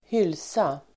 Uttal: [²h'yl:sa]